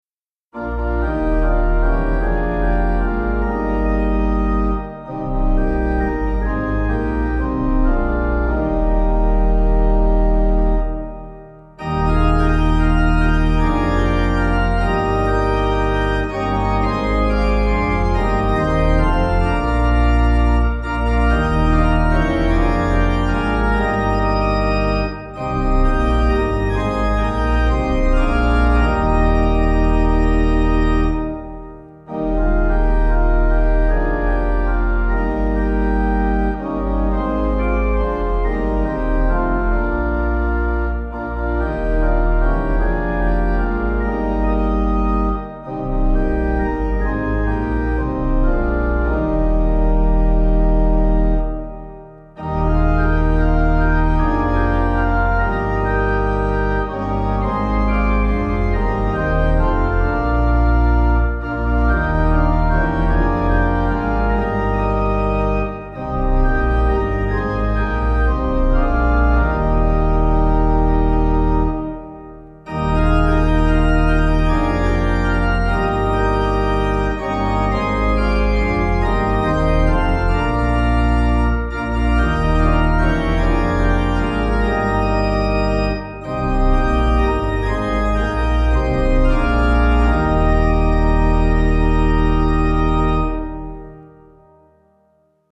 Key: E♭ Major